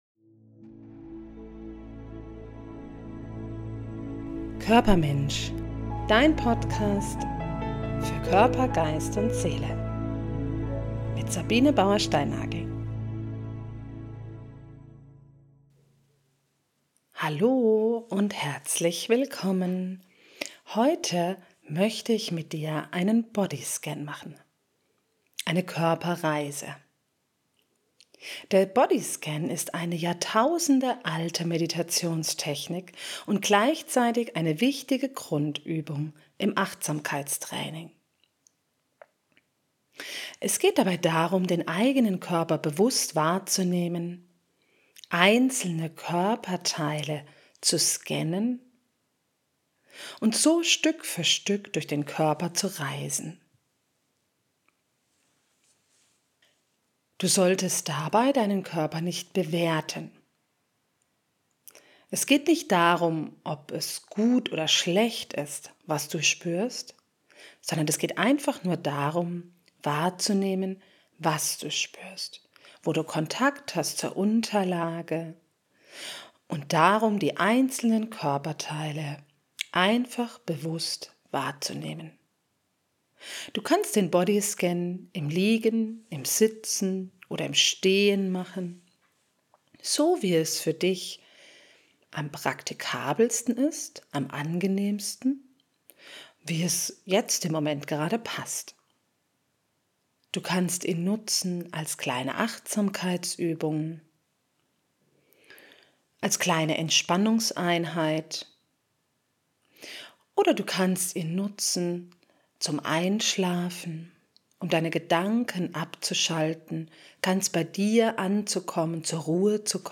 Beschreibung vor 1 Jahr Ich liebe den Bodyscan und verwende ihn als einzelne kurze Meditation, als Vorbereitung auf eine Entspannungseinheit, in der Hypnose und als Einschlafhilfe. Mache es Dir gemütlich und reise mit mir durch deinen Körper.
Bodyscan.mp3